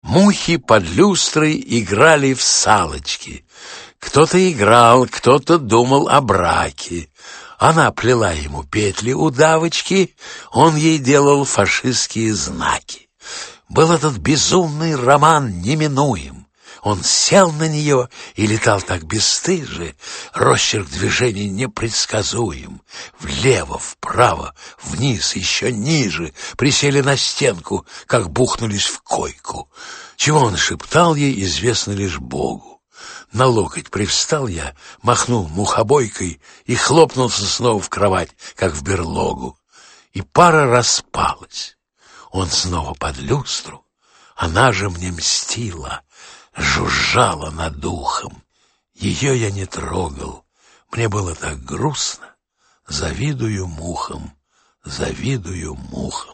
Аудиокнига Уже от мыслей никуда не деться…
Автор Валентин Гафт Читает аудиокнигу Валентин Гафт.